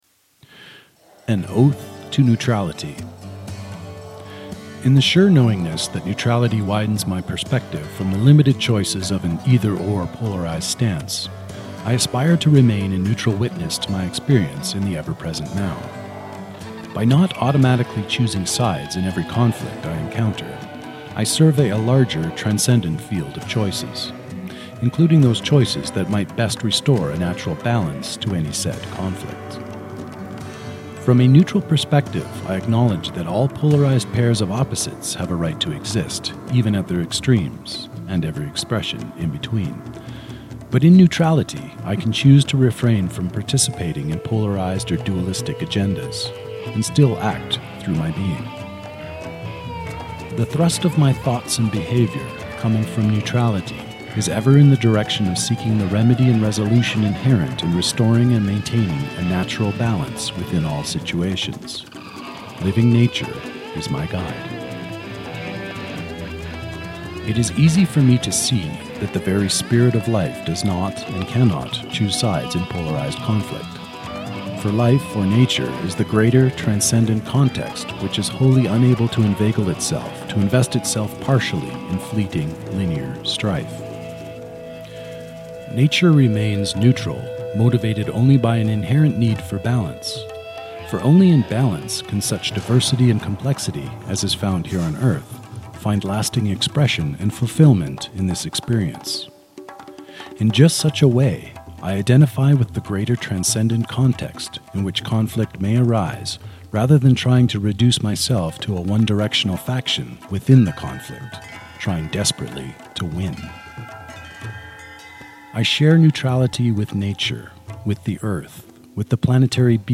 (Author Narration with musical accompaniment: Another Day by Steve Tibbetts)